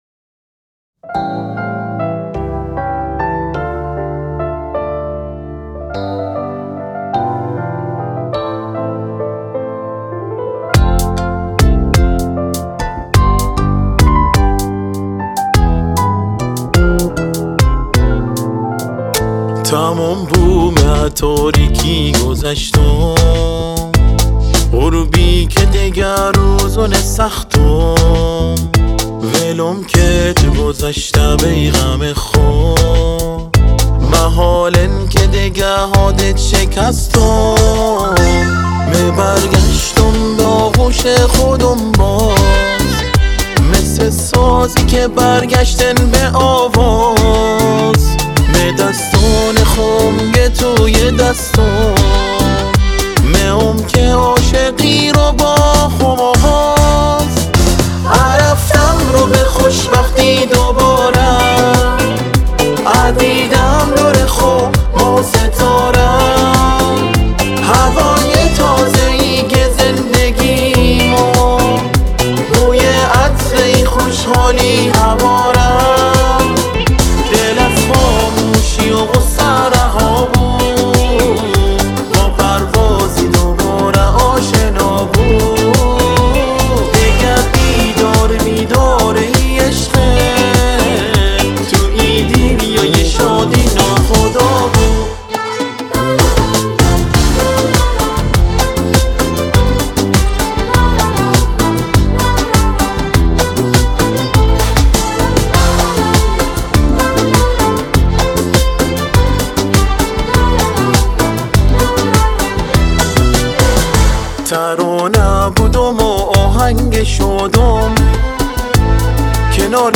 • • تنظیم و گیتار باس
• • گروه کُر